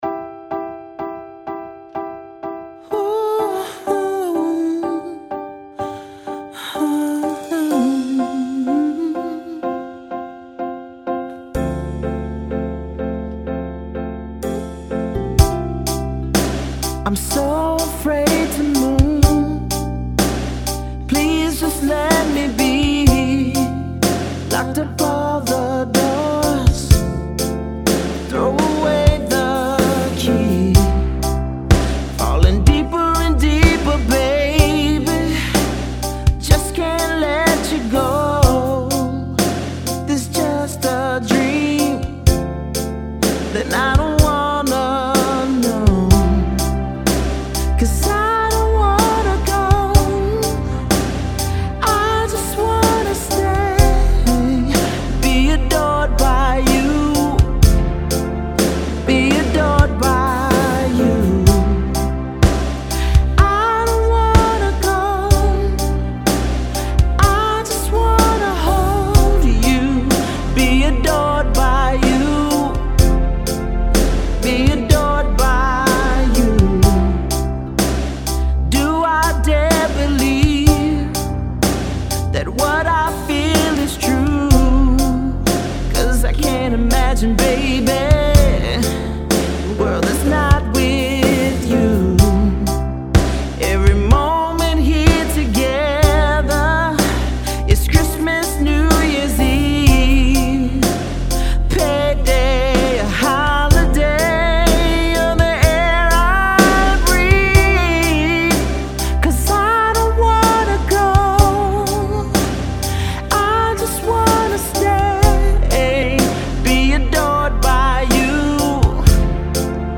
Mid Tempo Ml vx/drums/keys/bass